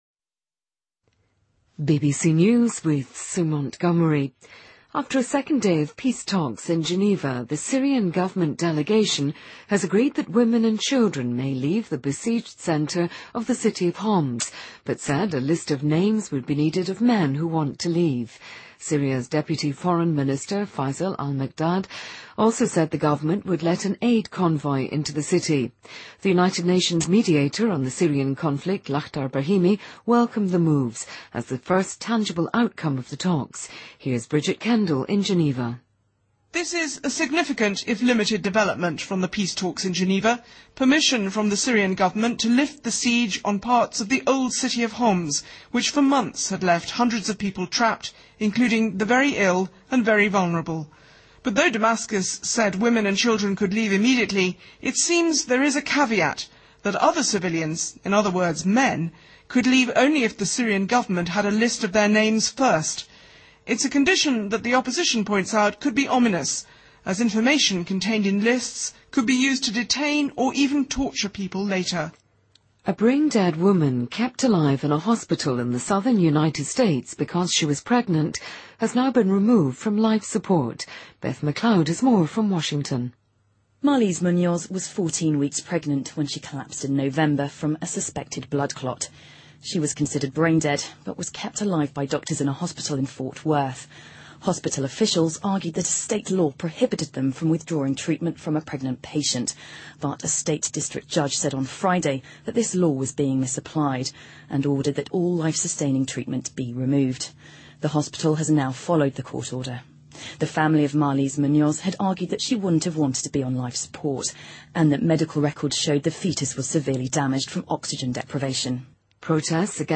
BBC news,2014-01-27